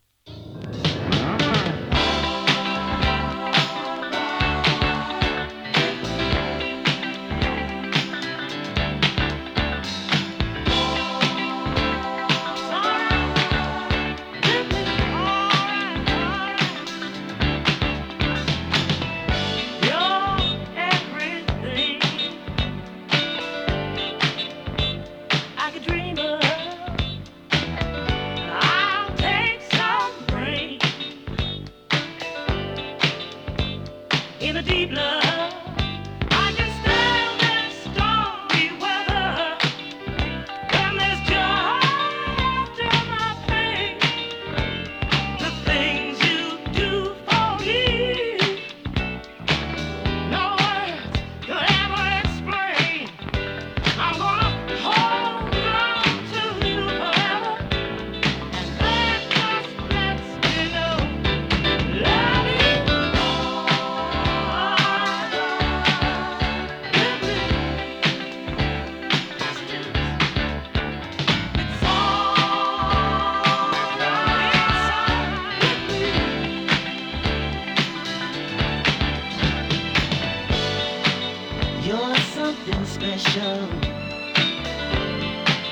切なくも力強さを感じさせるディスコ・ソウル！多くのダンスクラシックを持つ男女三人組ボーカルグループ。